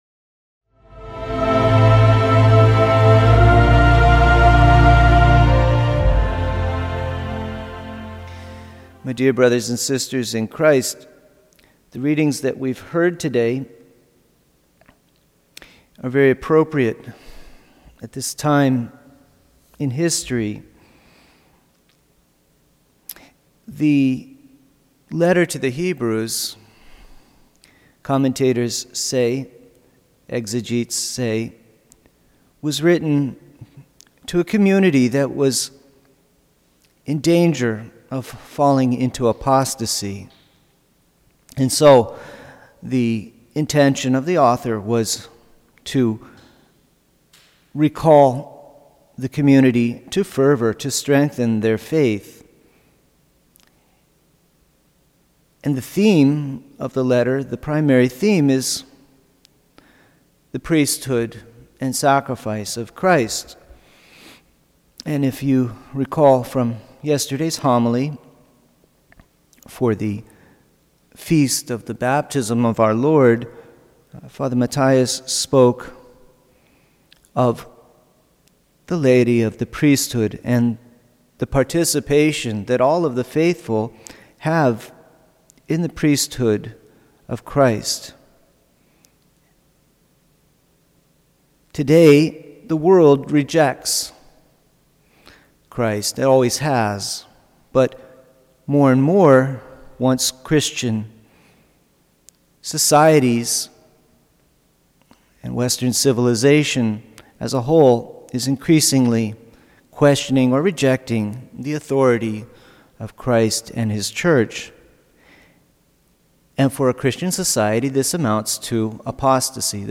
Homily
Mass: Tuesday 1st Week of Ordinary Time - Wkdy Readings: 1st: Heb 2:5-12 Resp: Ps 8:2, 5, 6-7, 8-9 0 Gsp: Mk 1:21-28 Audio (MP3) +++